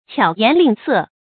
注音：ㄑㄧㄠˇ ㄧㄢˊ ㄌㄧㄥˋ ㄙㄜˋ
巧言令色的讀法